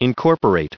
added pronounciation and merriam webster audio
1496_incorporate.ogg